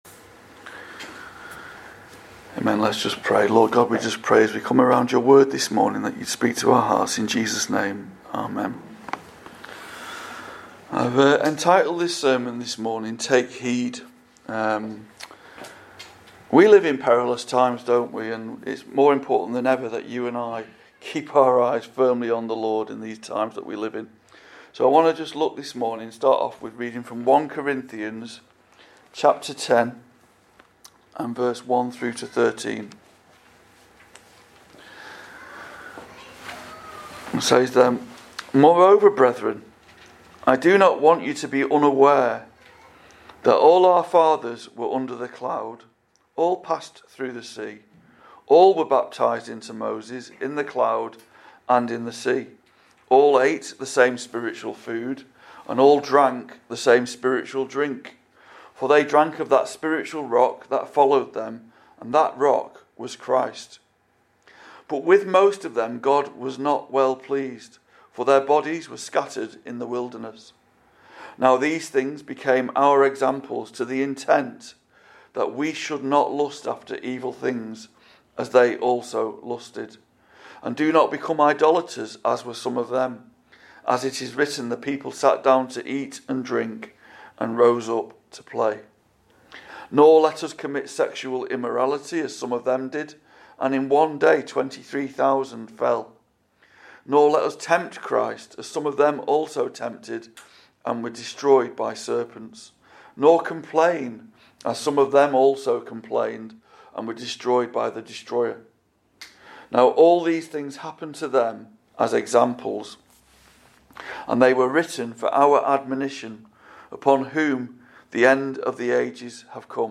Calvary Chapel Warrington Sermons / SERMON